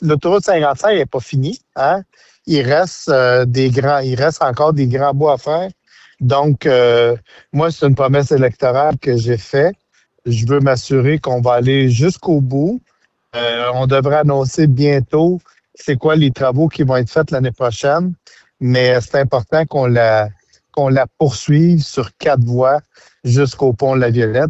C’est ce qu’a fait savoir en entrevue le ministre et député, Donald Martel.